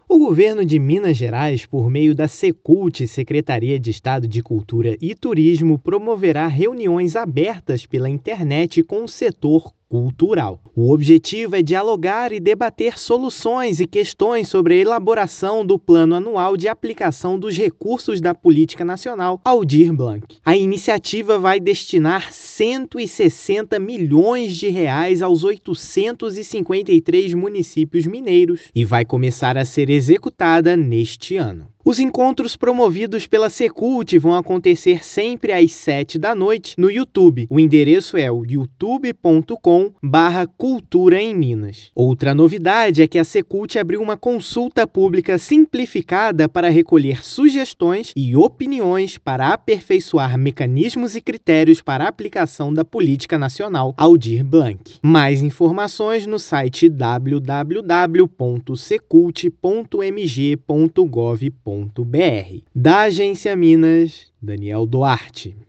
[RÁDIO] Governo de Minas realiza reuniões abertas com o setor cultural sobre a Política Nacional Aldir Blanc
Governo de Minas realiza reuniões abertas com o setor cultural sobre a Política Nacional Aldir Blanc. Ouça matéria de rádio: